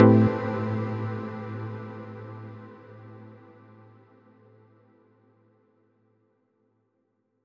menu-edit-click.wav